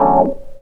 HOUSE 5-R.wav